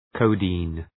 Προφορά
{‘kəʋdi:n}